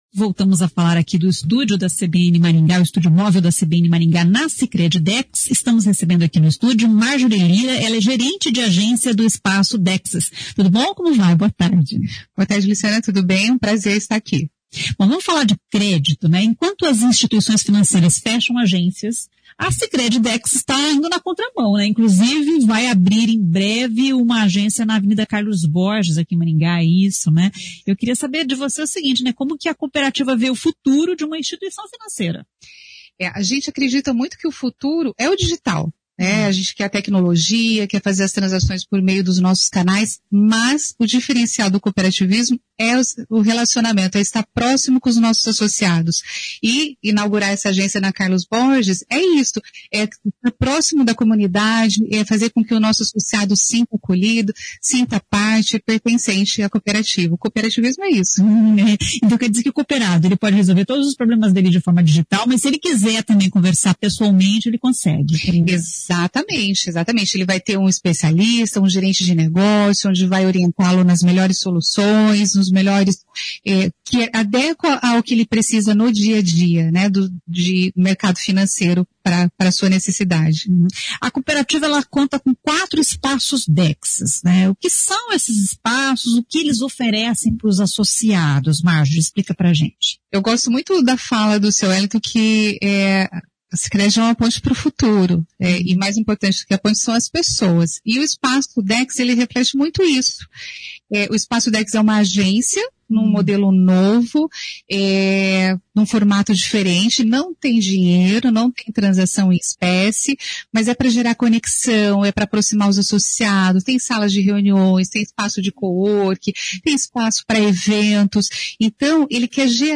Estúdio Móvel CBN